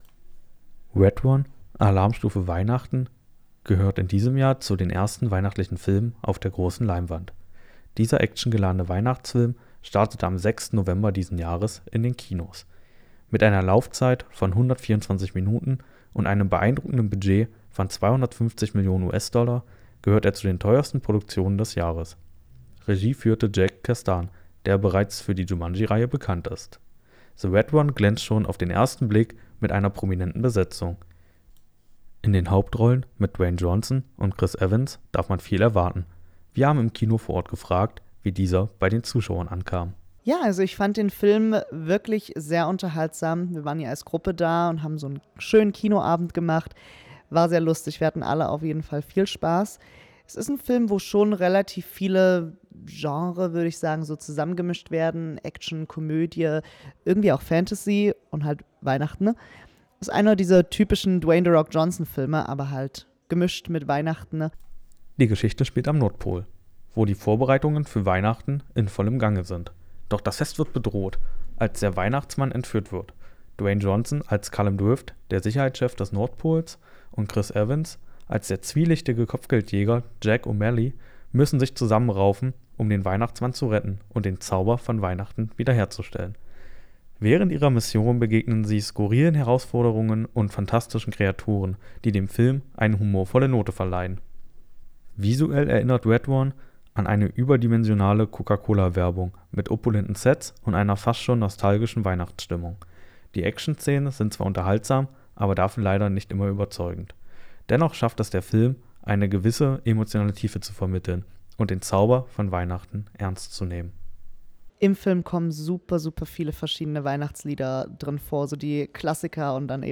Beitrag
Wir haben im Kino vor Ort gefragt, wie dieser bei den Zuschauern ankam.